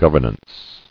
[gov·er·nance]